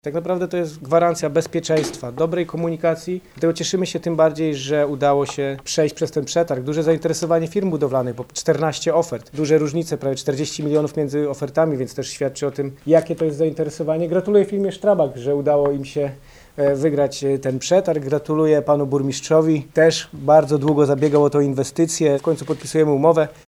Obwodnica zapewni szybsze połączenie z autostradą, Wrocławiem oraz drogą ekspresową S5. Do przetargu zgłosiło się aż 14 firm budowlanych, co pokazuje duże zainteresowanie – dodaje Michał Rado, wicemarszałek Województwa Dolnośląskiego.